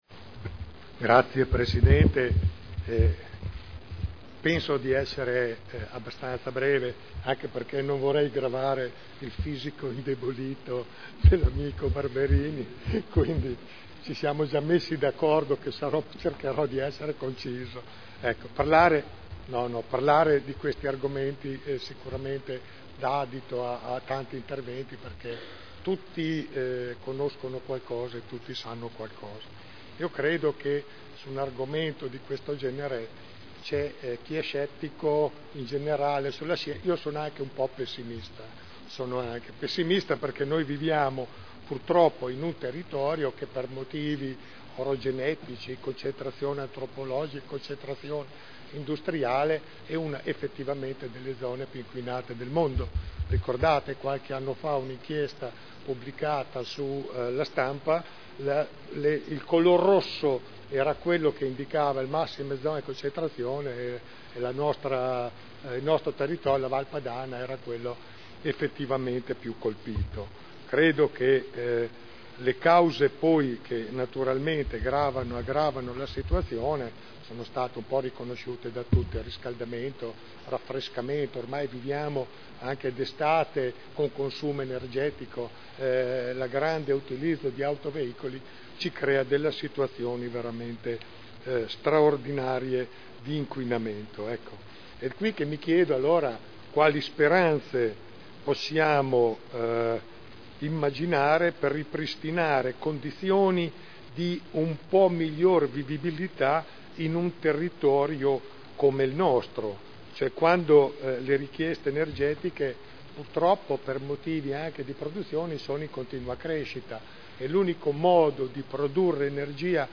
Giancarlo Pellacani — Sito Audio Consiglio Comunale
Seduta del 14/01/2010. Adesione del Comune di Modena all'iniziativa della Commissione Europea per la riduzione delle emissioni di anidride carbonica - Patto dei Sindaci (Covenant of Mayors) - Linee di indirizzo per il Piano d'Azione